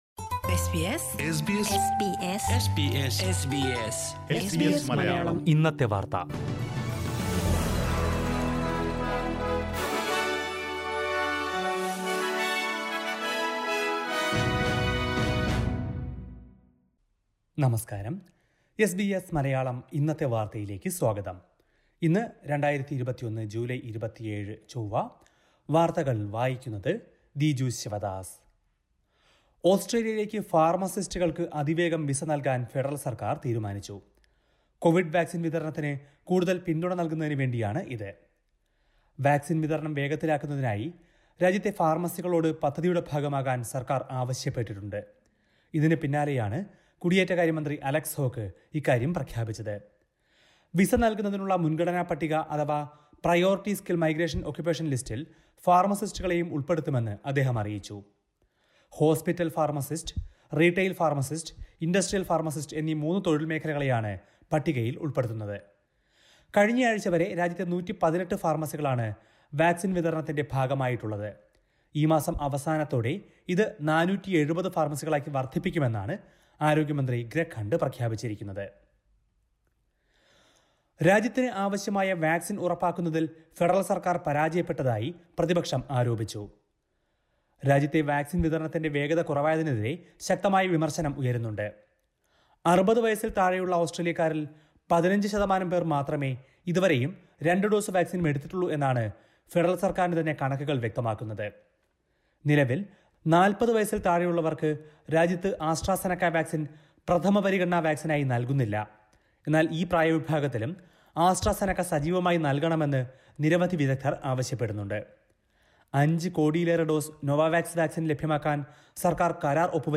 2021 ജൂലൈ 27ലെ ഓസ്ട്രേലിയയിലെ ഏറ്റവും പ്രധാന വാർത്തകൾ കേൾക്കാം...